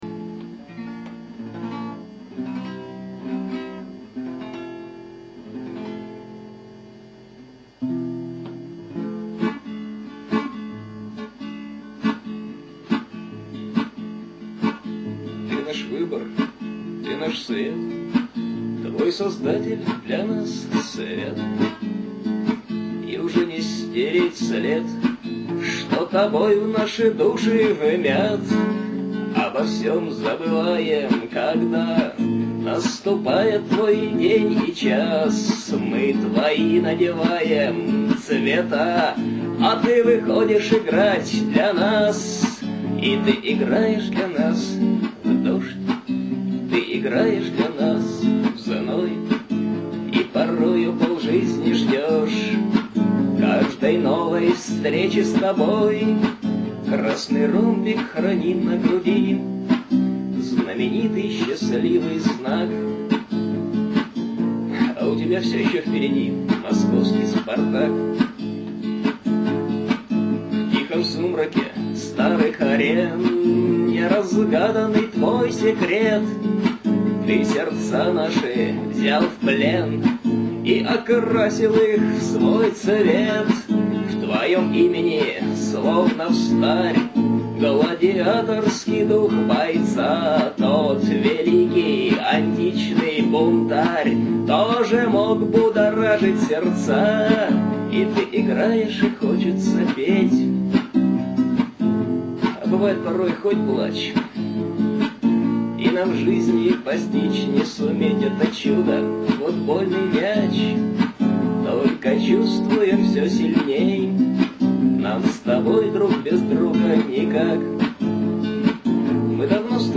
Это ни в коем случае не гимн, это вообще не стадионная песня, и я даже не представляю, можно ли ее исполнить хором. :-)) Только гитара и голос. Более того, до сих пор существует всего один-единственный авторский «аудиовидеодубль», :-) записанный почти полтора года назад с помощью обычной цифровой видеокамеры, без применения какой бы то ни было профессиональной звукозаписывающей аппаратуры, и преобразованный сейчас в mp3-файл .